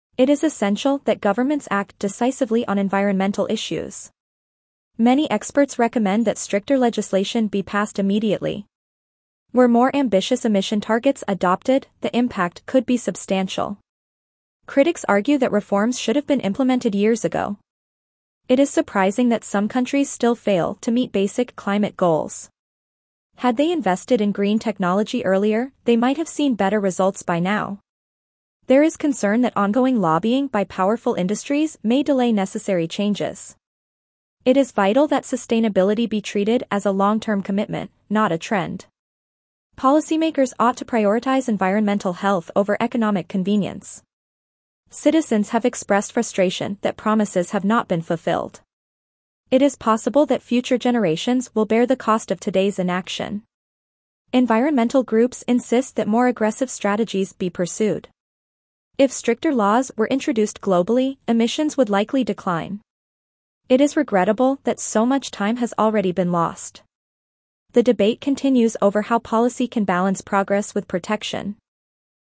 Your teacher will read the passage aloud.
(Teacher’s Script – 15 Sentences):